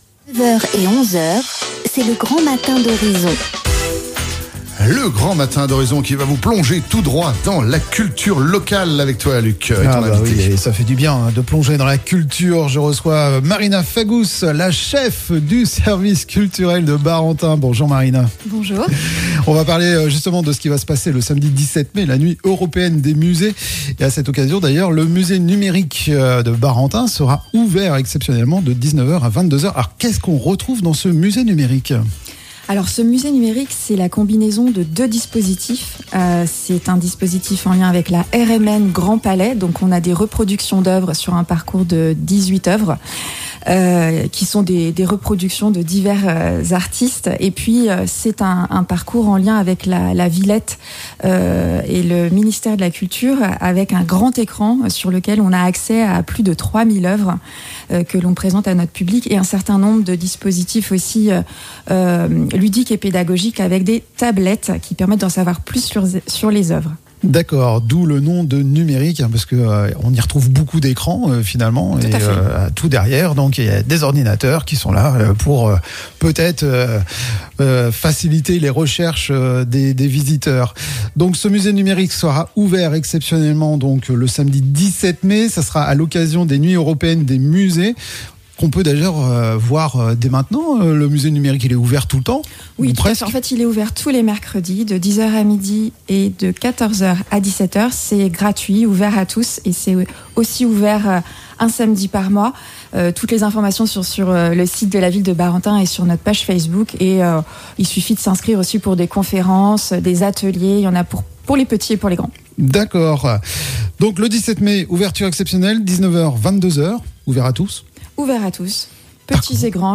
Interview Horizon Nuit Européenne des Musées